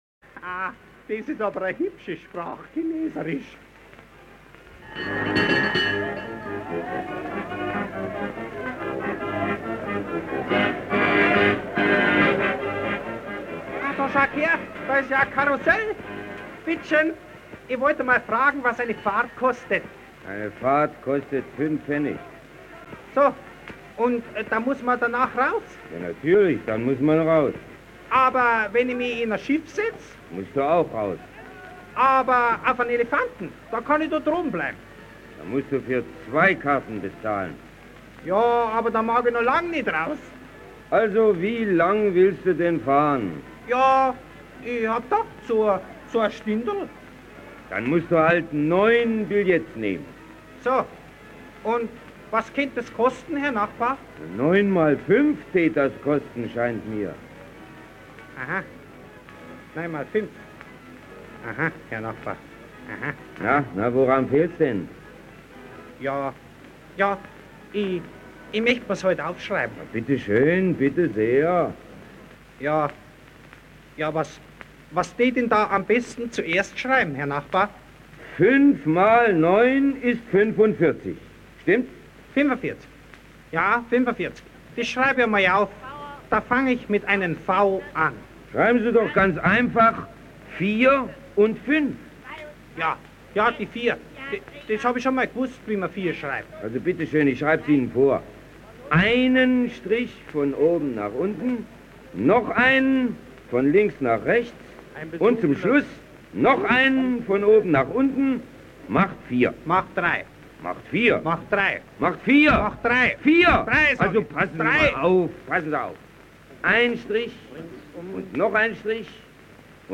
Radau um Kasperl, was broadcast by Westdeutscjer Rundfunk, 9 September 1932, in Cologne, Germany.
Two portions of Walter Benjamin’s original performance of Radau um Kasperl survive.